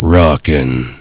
Worms speechbanks
Flawless.wav